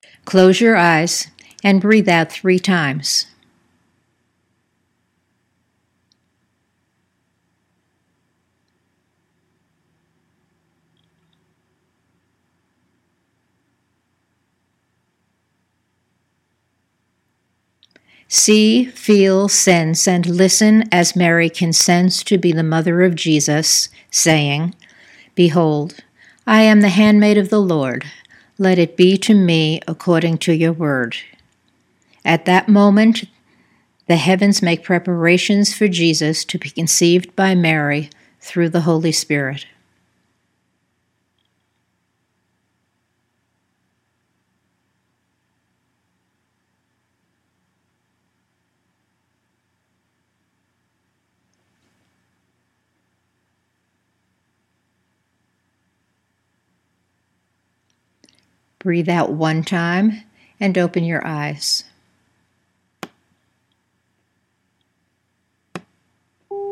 NOW FOR OUR ADVENT NARRATIVE WITH AUDIO IMAGERY (These exercises are not designed for young children.  Adults may use these narratives and/or Imagery as their Advent Wreath Prayers)